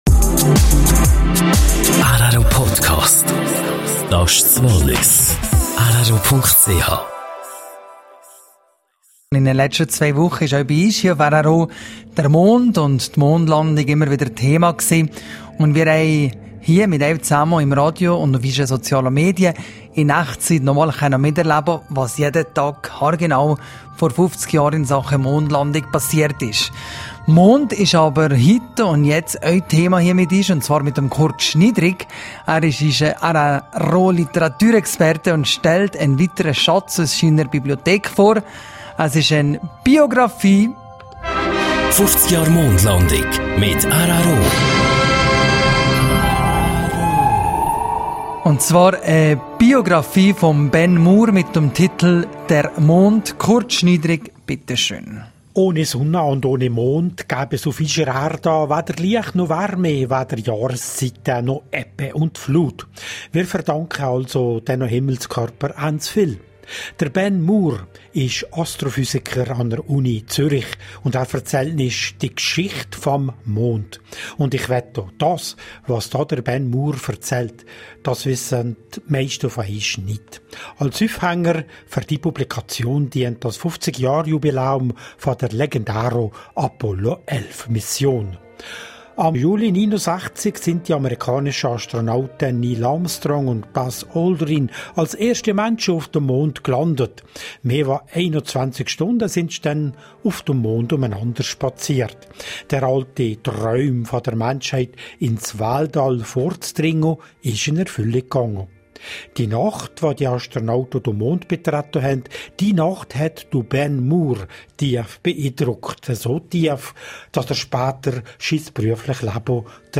Erfahren Sie mehr im Kurzbeitrag auf rro.